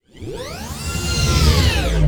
hangar2.wav